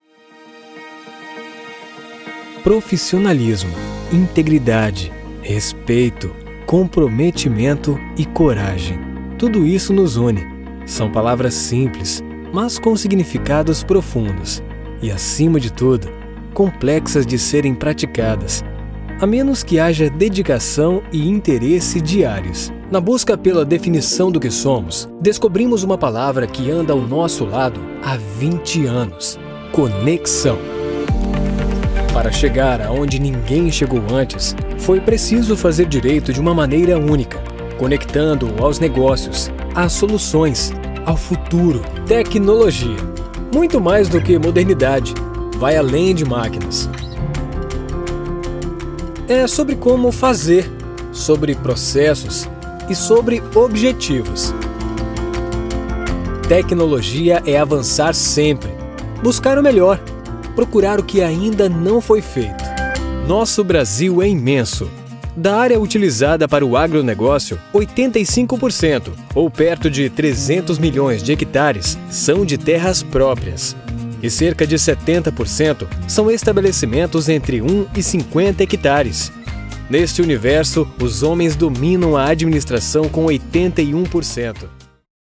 Masculino
Voz Padrão - Grave 01:25
Além de equipamentos profissionais devidamente atualizados, todas as locuções são gravadas em cabine acústica, resultando em um áudio limpo e livre de qualquer tipo de interferência.